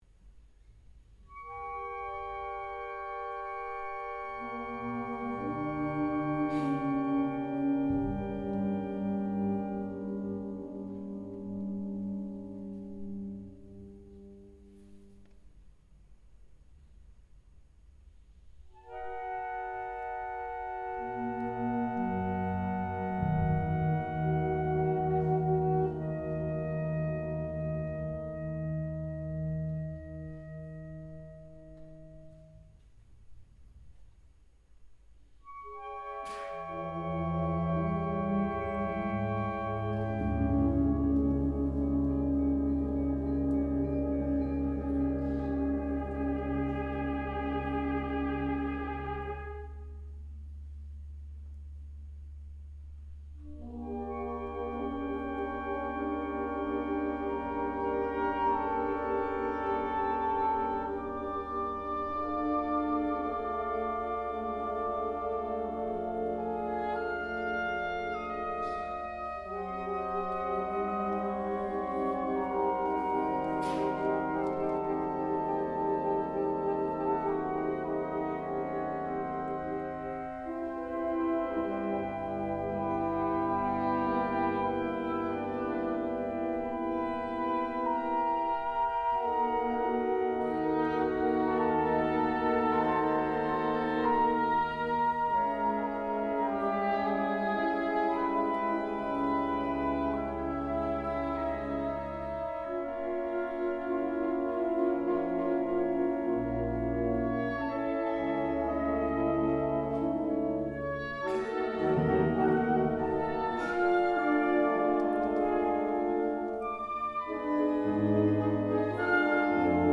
Category Concert/wind/brass band
Subcategory Contemporary Wind Music (1945-present)
Instrumentation Ha (concert/wind band); CB (Concert Band)